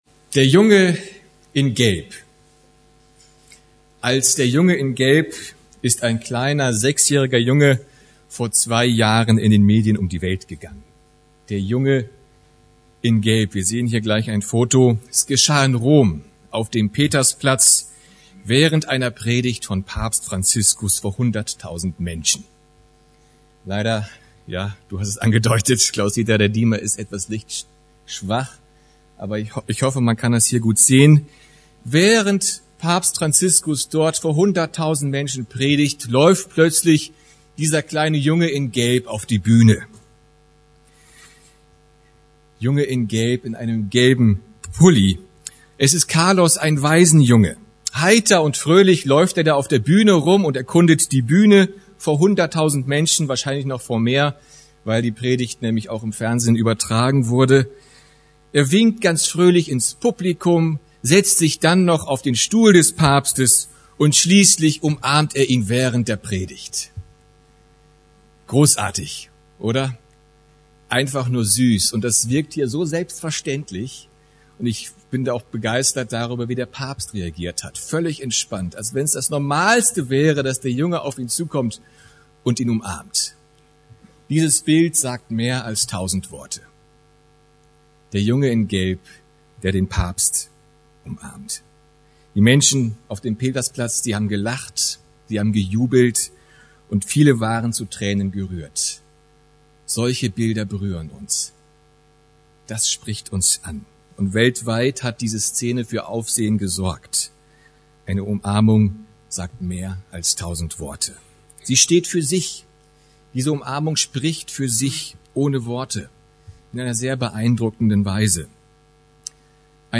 Serie: Einzelpredigten